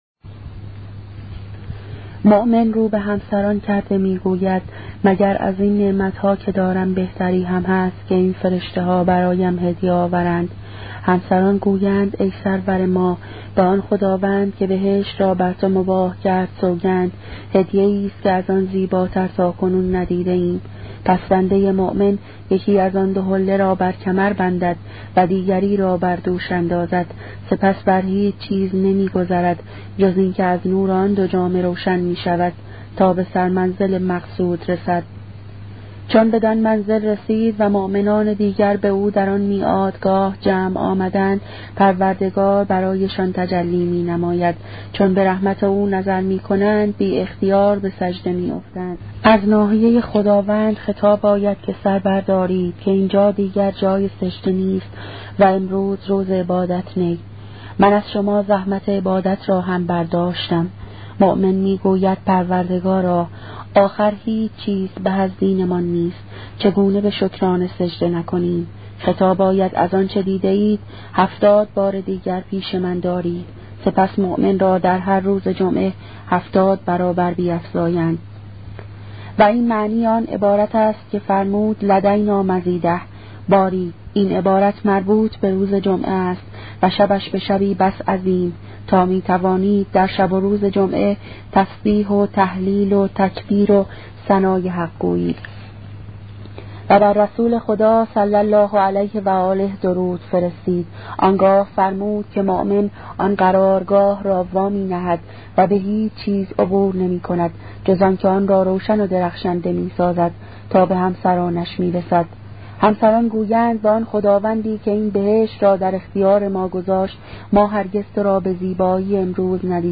کتاب صوتی عبادت عاشقانه , قسمت ششم